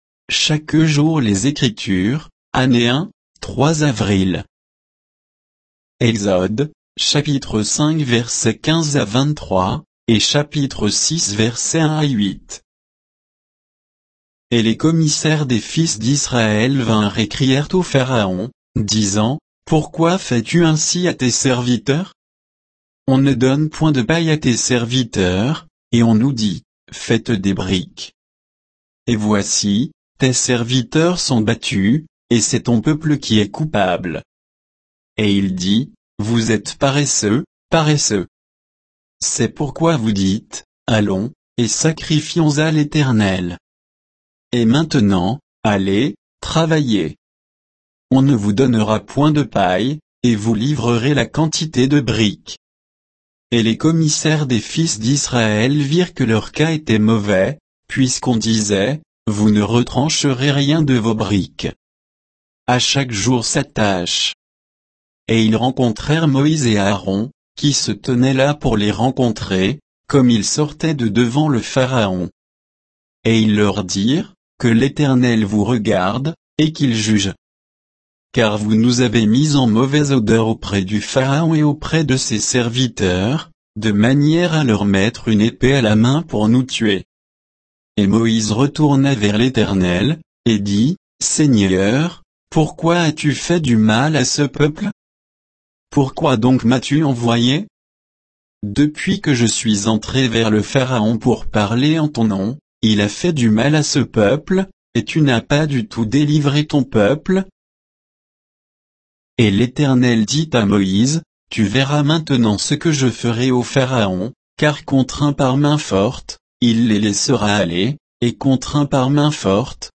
Méditation quoditienne de Chaque jour les Écritures sur Exode 5, 15 à 6, 8